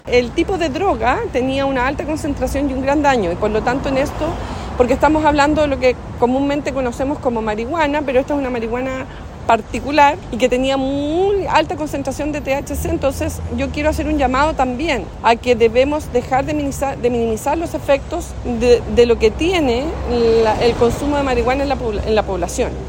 En tanto, la seremi de Seguridad Pública del Bío Bío, Paulina Stuardo, se refirió a los riesgos asociados al consumo de sustancias ilícitas y destacó la importancia de este tipo de incautaciones en la prevención del delito.